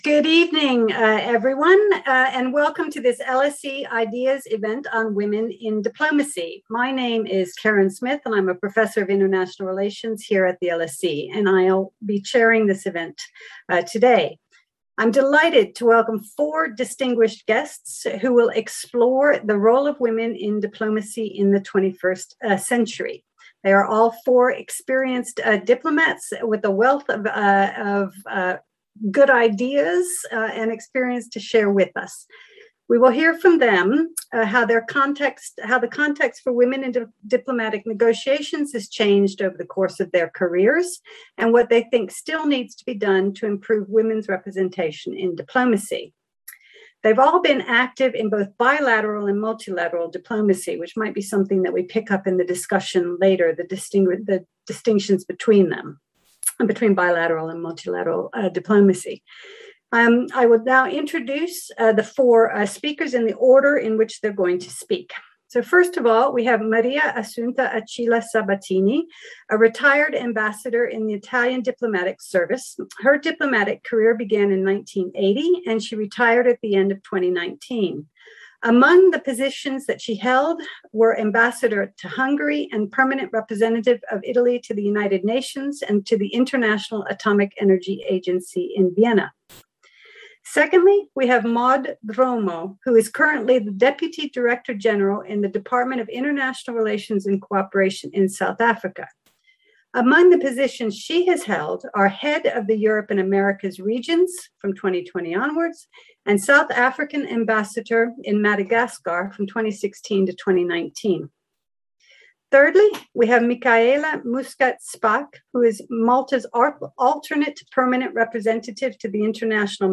Explore the role of women in diplomacy in the 21st century at this LSE IDEAS webinar.